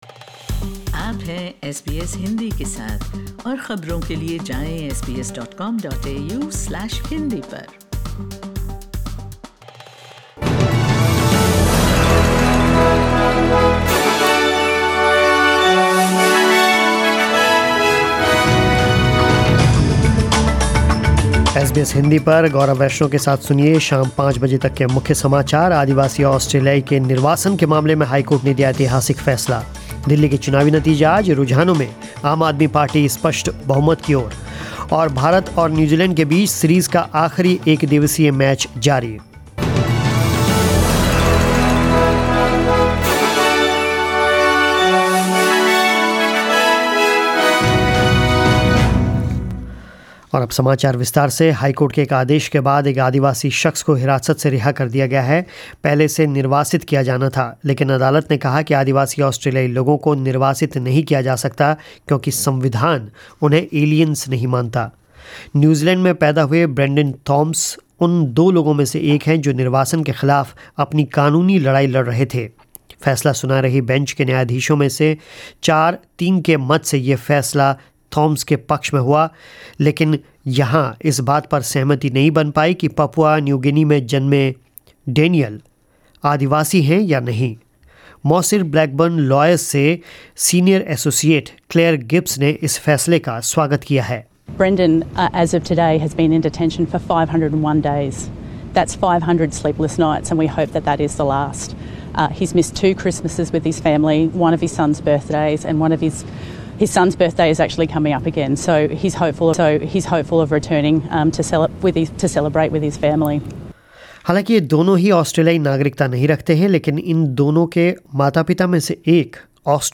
News in Hindi 11 Feb 2020